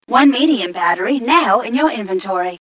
1 channel
mission_voice_ghca003.mp3